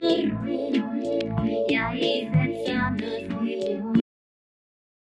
Note that the input signal already contains impulsive "cracks", which some of the effects may emphasize.
Wah-wah
Lower band center frequency 200 Hz, bandwidth 1 octave, band separation 2 octaves, modulation frequency 2 Hz, modulation width 100 Hz
sf_wah1.wav